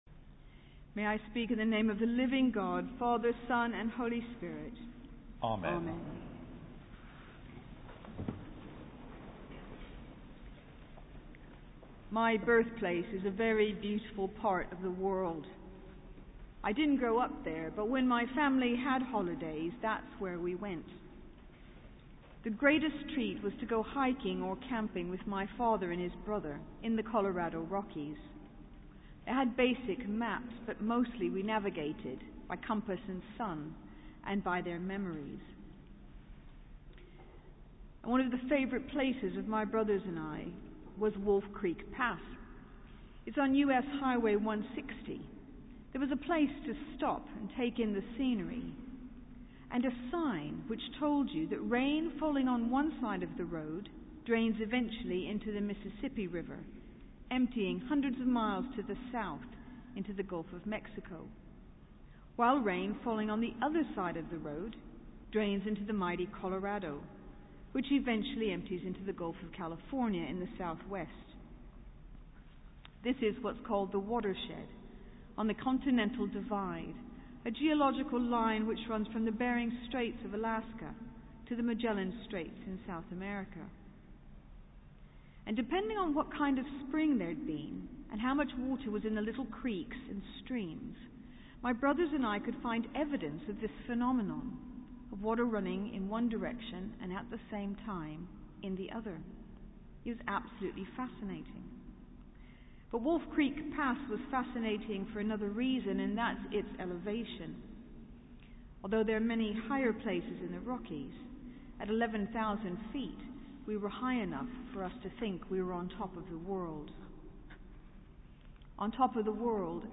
Sermon: Candlemas Service - 1 February 2015